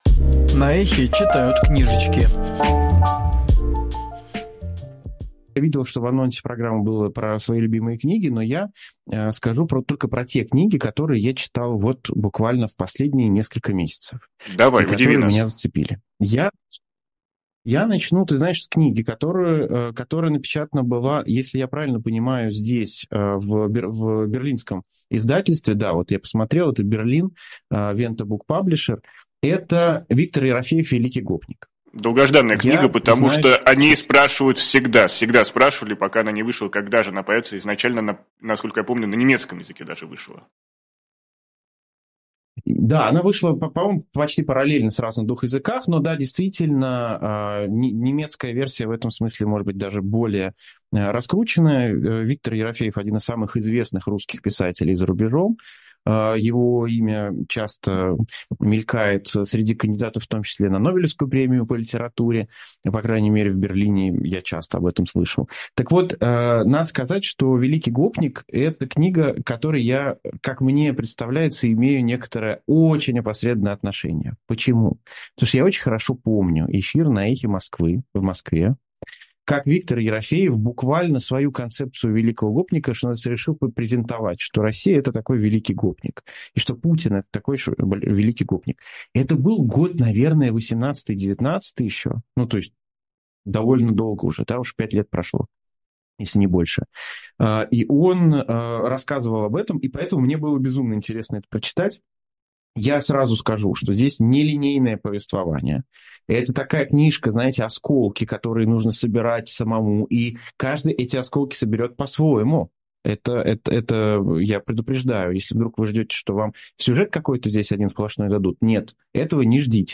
Фрагмент эфира «Книжное казино» от 4 ноября